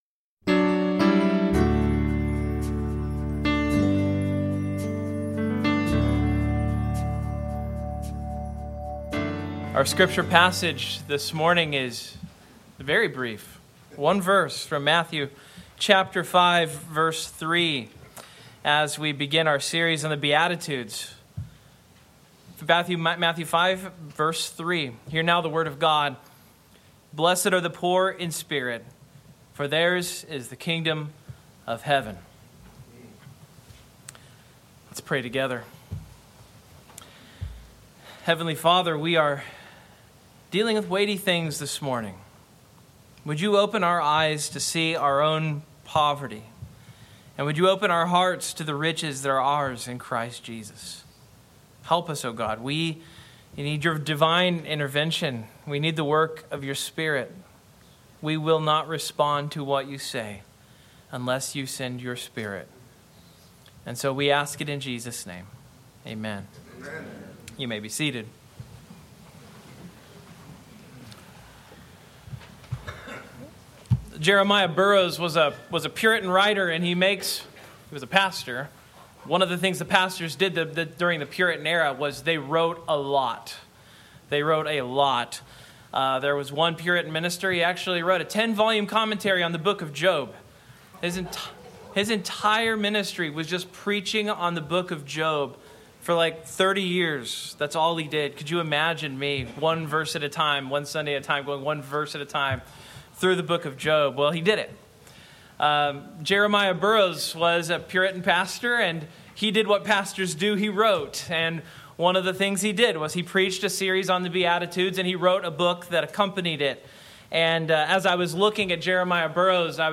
Matthew 5:3 Service Type: Morning Outline